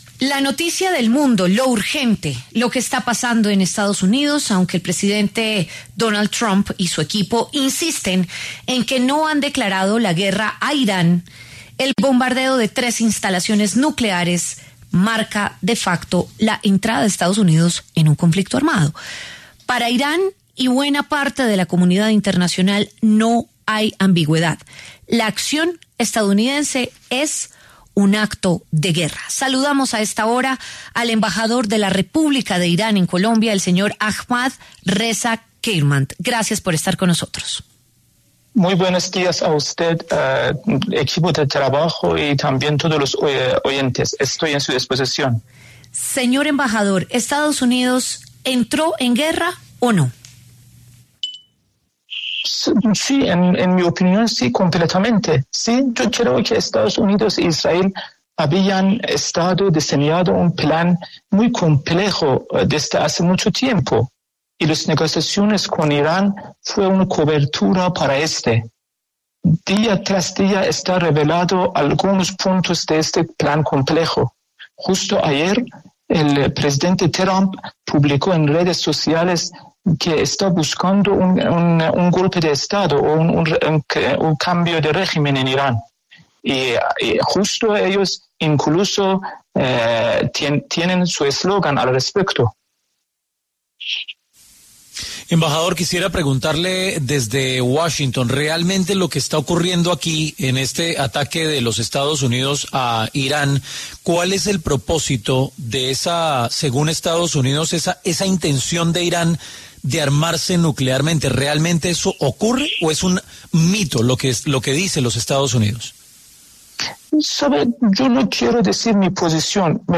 Ahmad Reza Kheirmand, embajador de Irán en Colombia, se refirió en La W a la amenaza de su país a los Estados Unidos tras los ataques contra sus instalaciones nucleares.